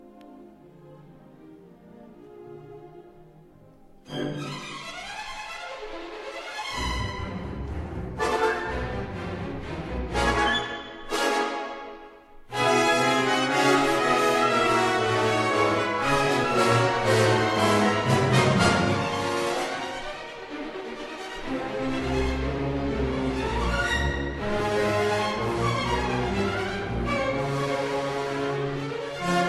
یک تجربه ی سمفونیک برای ارکستر